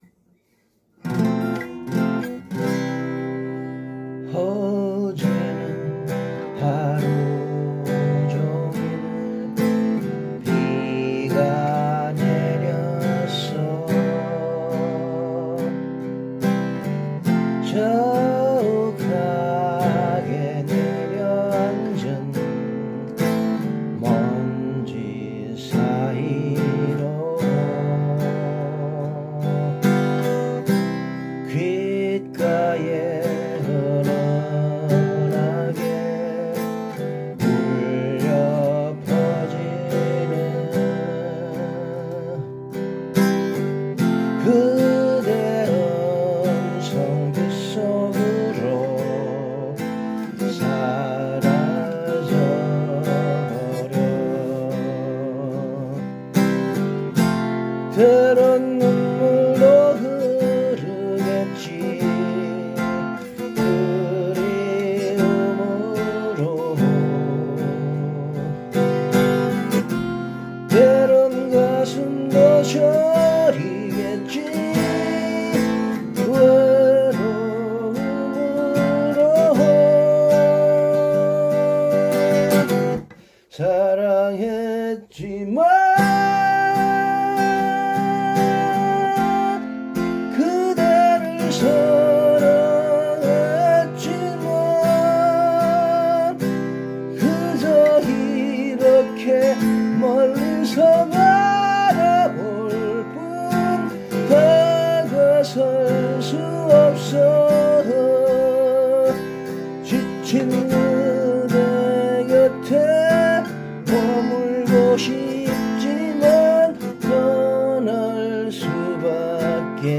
이번 글에서는 C Major 조성
아래는 제가 실제로 기타를 치면서 노래를 부른것 입니다.
기타연주 및 노래